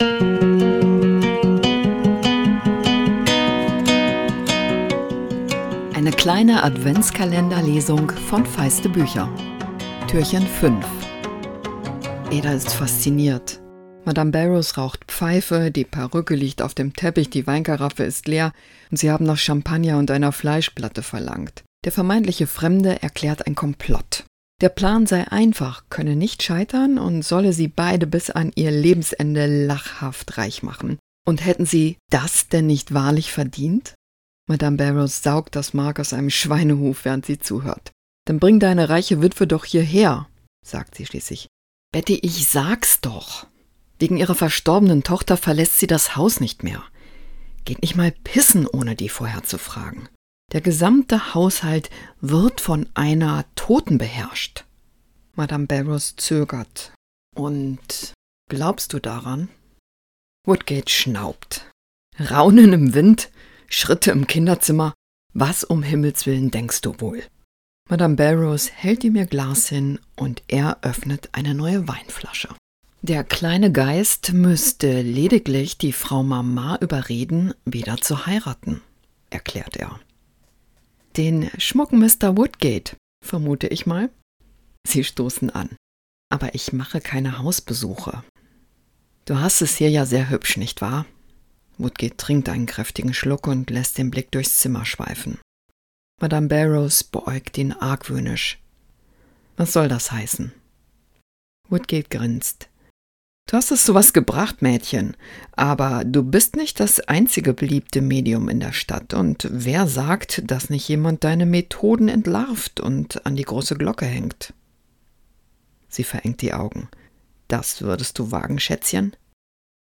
Adventskalender-Lesung 2024!